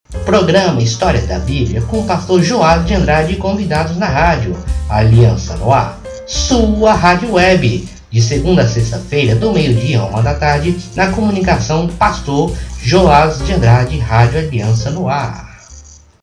CHAMADA DE RÁDIO